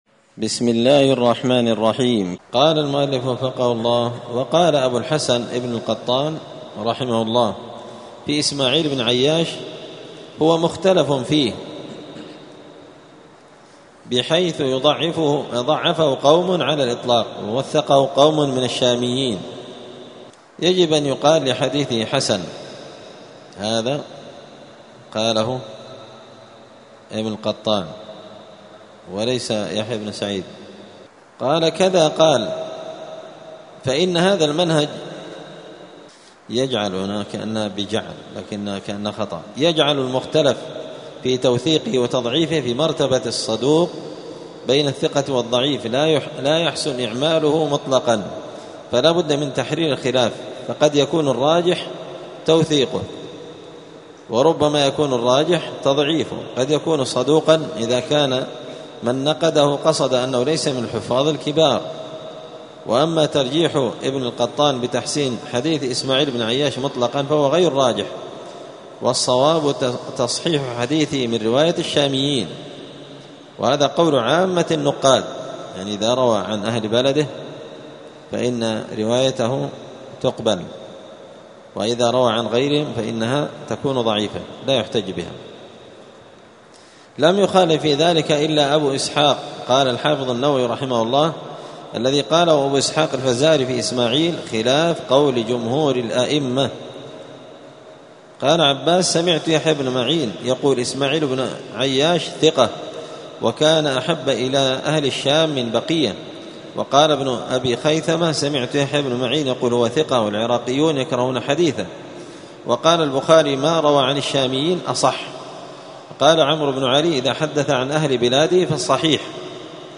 *الدرس الخامس والخمسون (55) تابع لباب تجاذب الجرح والتعديل.*